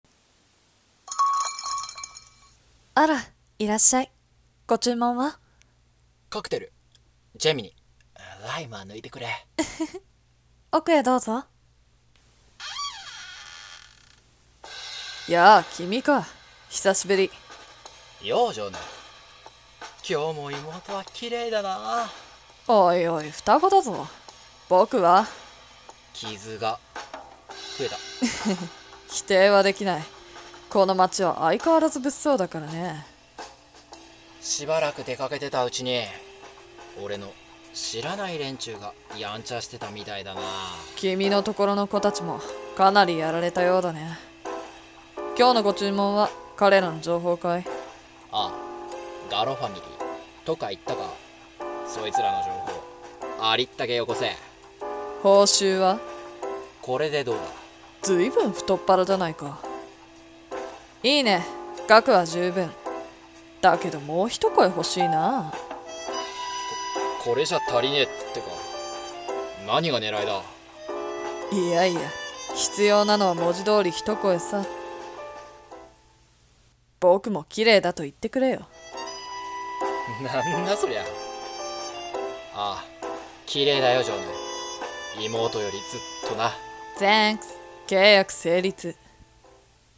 【声劇台本】Geminiのカクテル